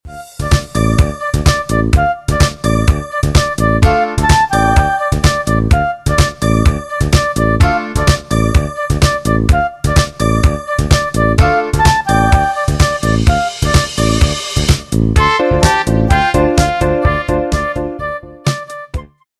fichier midi
karaoké